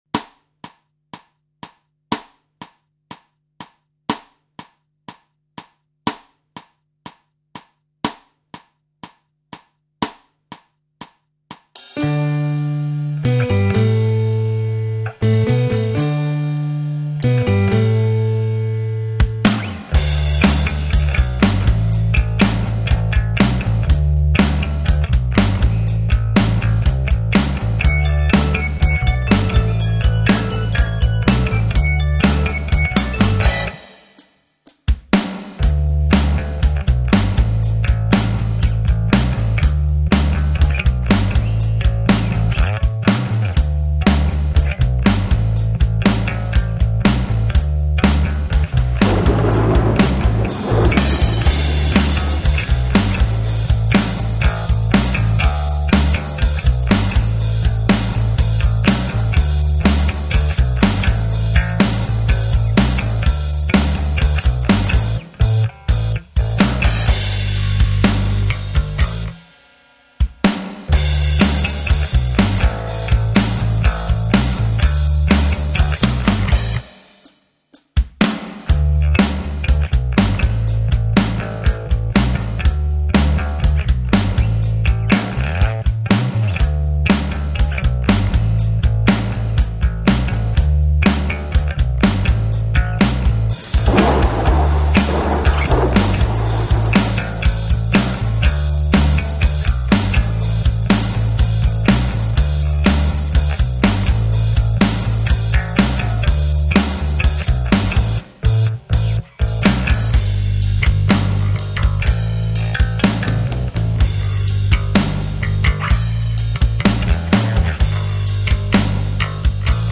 Tempat Download Backing Track